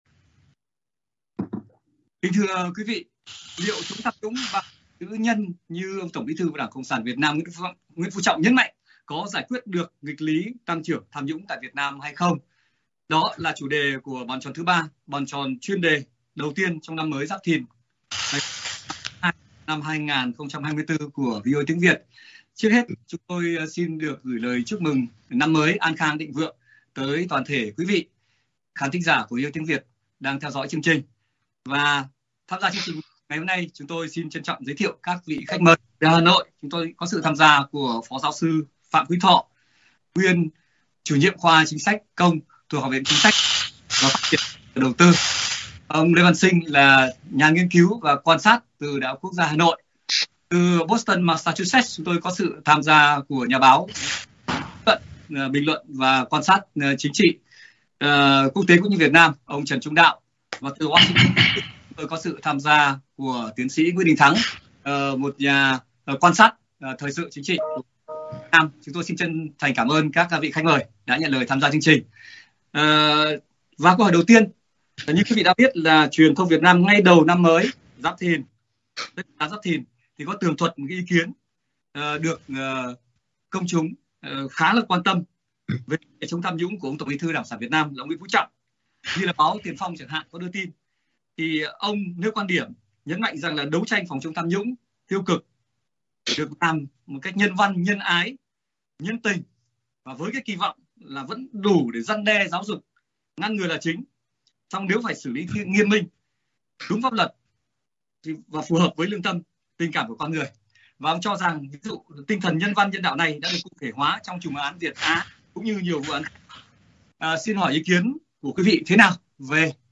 Có nghịch lý gì giữa tăng trưởng kinh tế và nạn tham nhũng tại Việt Nam hay không và liệu chống tham nhũng bằng ‘chữ nhân’ như TBT ĐCSVN Nguyễn Phú Trọng mong muốn có khả thi không là chủ đề của Bàn Tròn Thứ Ba, bàn tròn chuyên đề của VOA Tiếng Việt đầu năm Giáp Thìn.